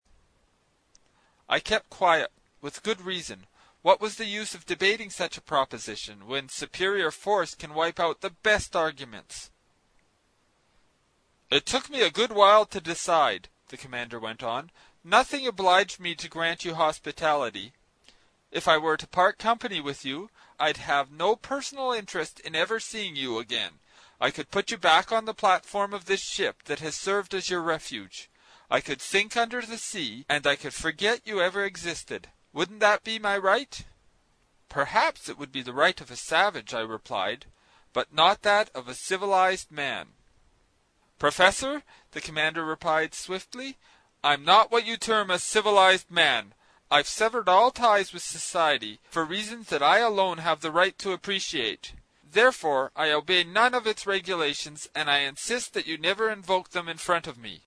英语听书《海底两万里》第135期 第10章 水中人(6) 听力文件下载—在线英语听力室
在线英语听力室英语听书《海底两万里》第135期 第10章 水中人(6)的听力文件下载,《海底两万里》中英双语有声读物附MP3下载